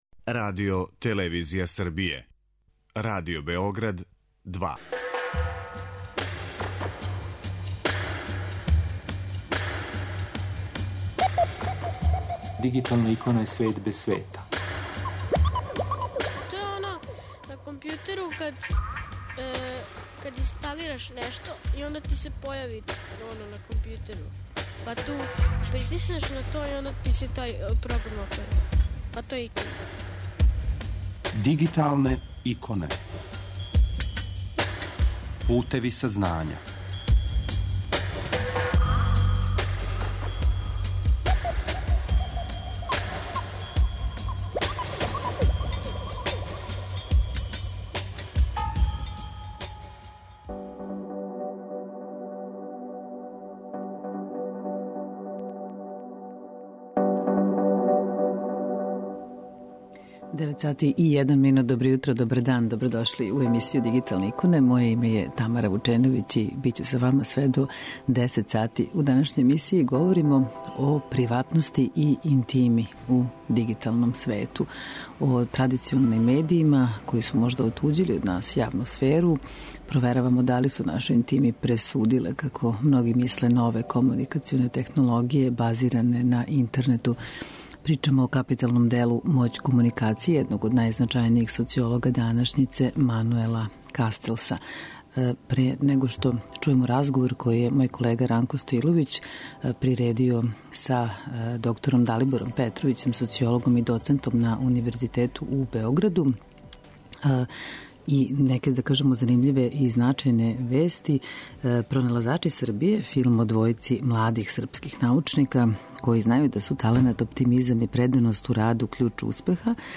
Емитујемо разговор